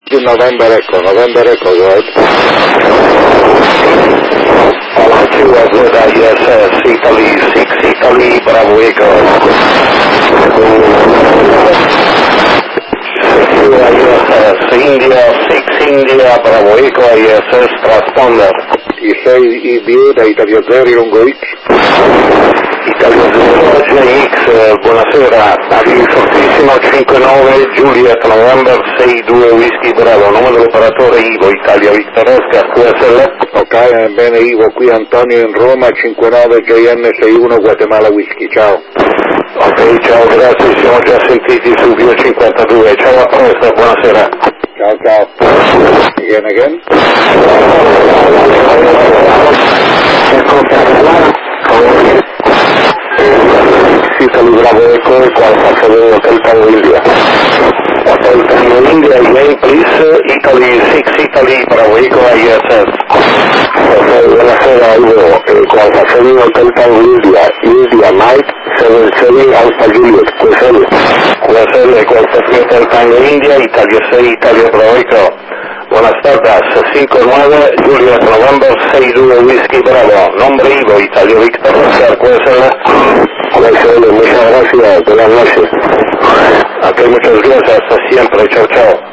modo operativo VOCE - uplink 437.800 Mhz FM , downlink 145.800 Mhz FM + - Doppler